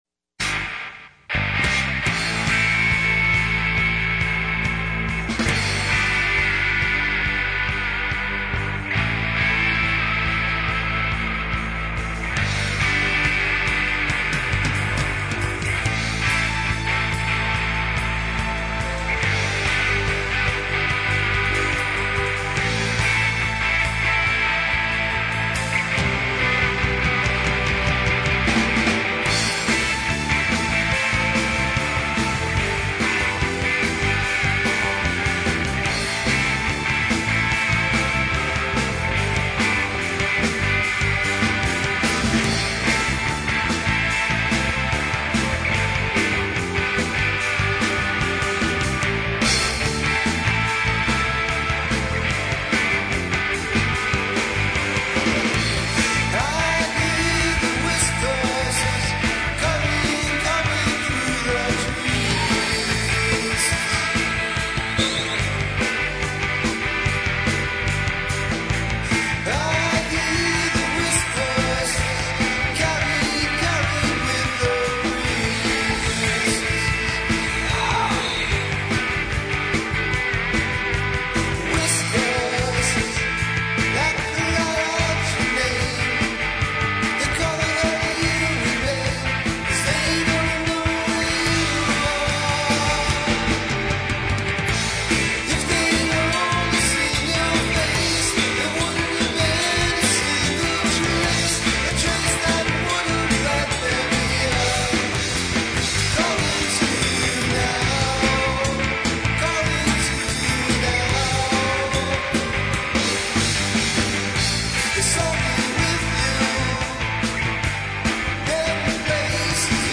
Keyboards
Drums
Bass
Guitar